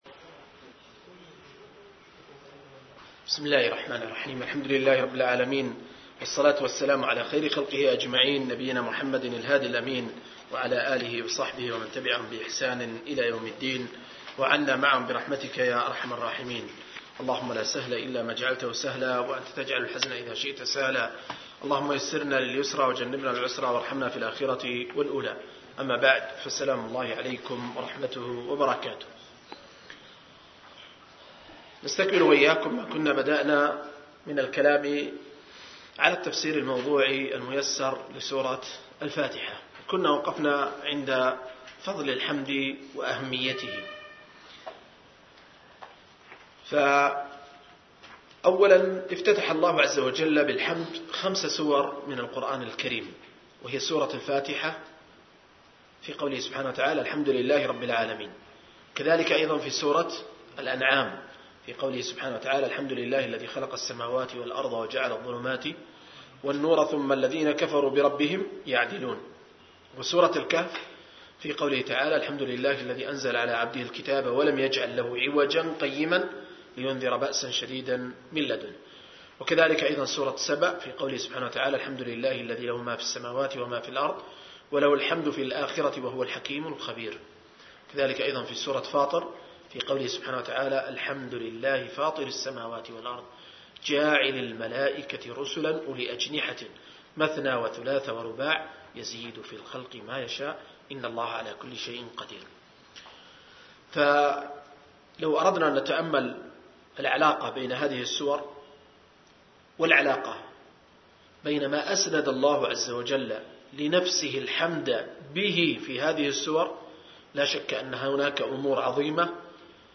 04-التفسير الموضوعي الميسر لقصار المفصل – الدرس الرابع